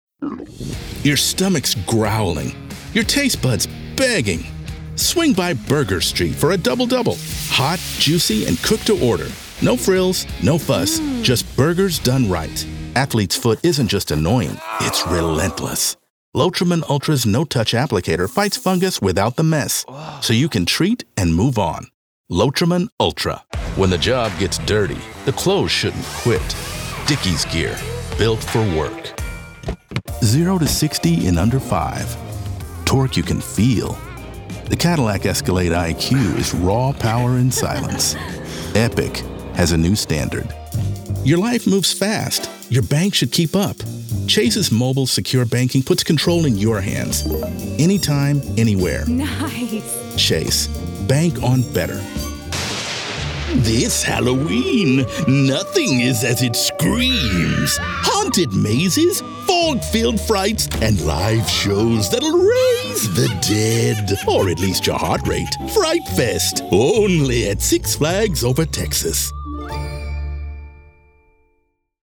Commercial Demo
English - USA and Canada
Middle Aged